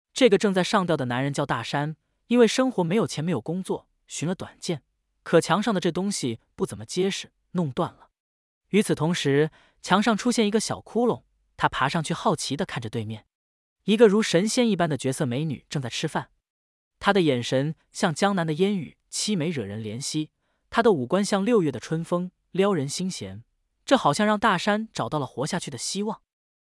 时常刷抖音的朋友们，应该经常刷到这样的电影解说:
这个大家耳熟能祥的配音，实际都来源于微软AI人工智能语音接口：云希，他的音色自然，情感丰富，语速可调，这就早就了自媒体电影解说人都在用它来做配音。